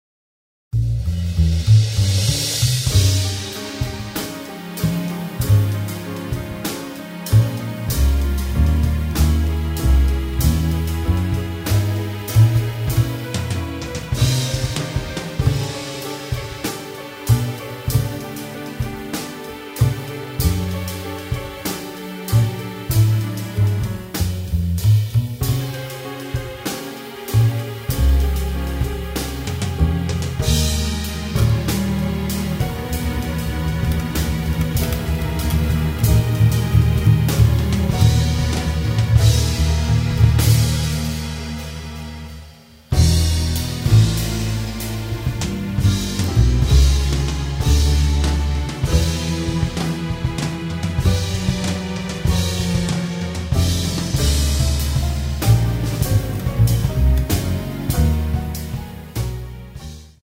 electric guitars & MIDI programming
drums
electric bass
piano
tenor saxophone